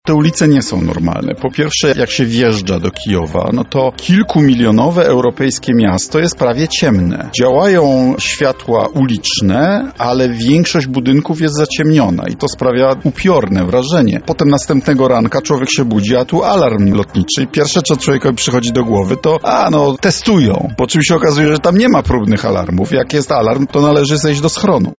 Gospodarka Ukrainy wpadła w koszmarną recesję – tak w dzisiejszej Porannej Rozmowie Radia Centrum ocenił nasz gość Radosław Sikorski, europoseł z ramienia PO, były szef MON i MSZ.